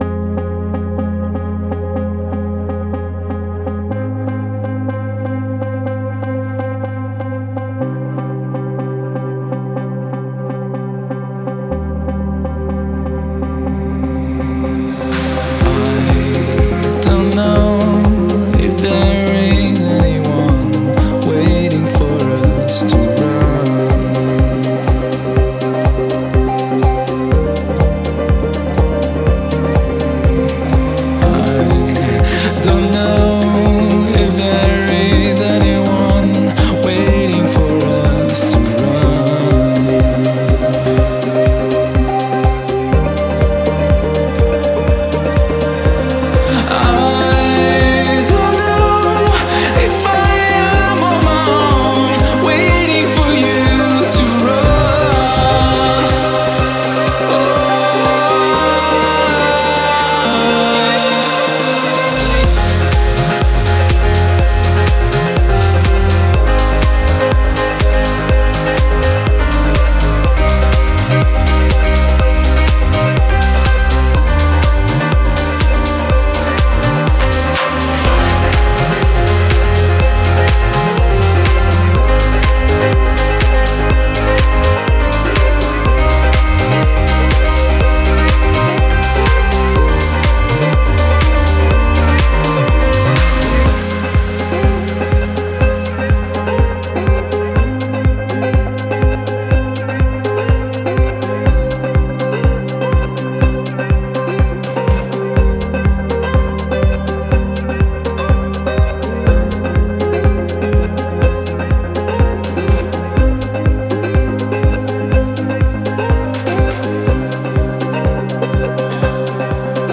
это энергичная электронная композиция